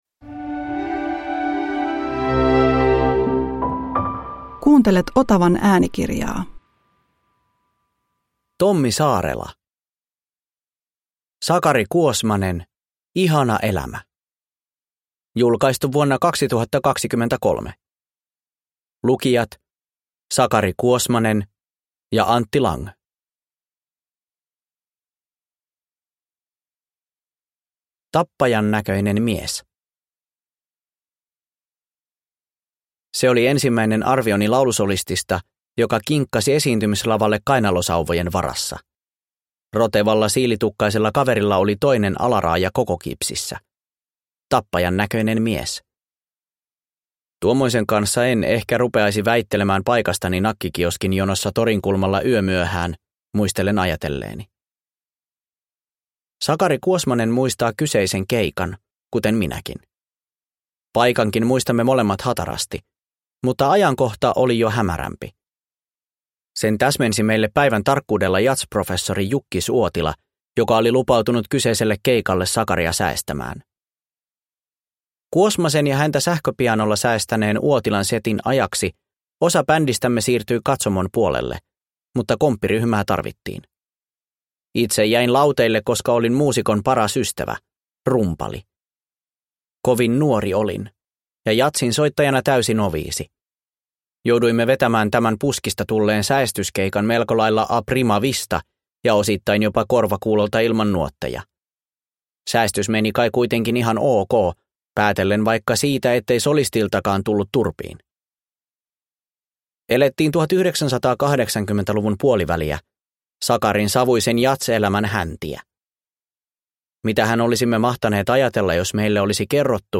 Sakari Kuosmanen – Ljudbok – Laddas ner